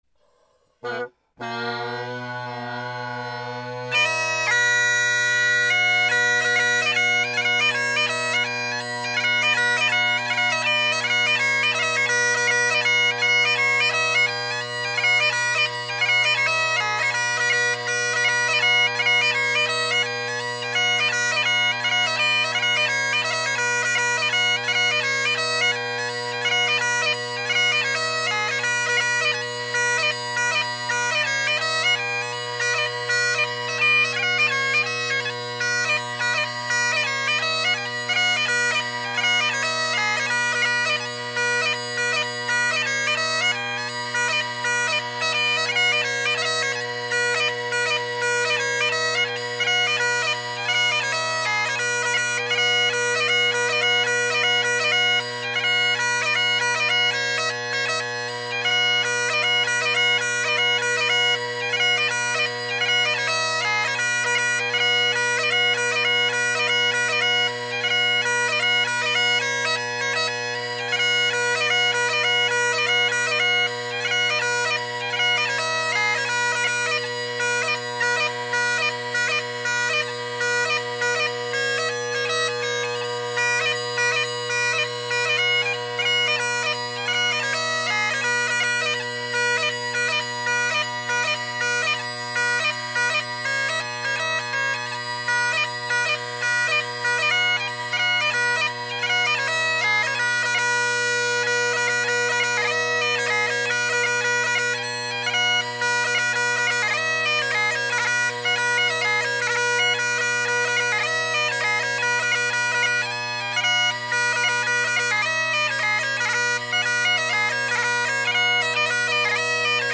Great Highland Bagpipe Solo
The first track was meant to exhibit the very awesome high A, except it went sharp about 20 seconds in so you’ll have to catch a glimpse from the latter two recordings, Song for Winter being a good one, F too, and B, good chanter.
As it stands, the first recording is just an example of how inconsistent my grips are, one day I’ll get that tune down.